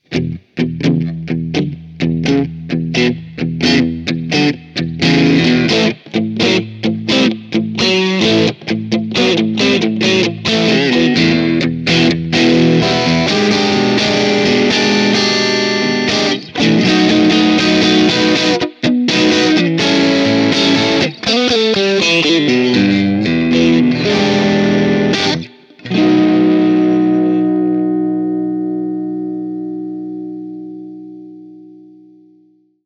Tutte le clip audio sono state registrate con testata a valvole artigianale e cassa 2×12 equipaggiata con altoparlanti Celestion Creamback 75 impostato su un suono estremamente clean.
Chitarra: Fender Stratocaster (pickup al manico)
Genere: Rock/Blues
Boost: 7/10
Twang: 8/10
Blackface Output: 4/5
Blues-Strat.mp3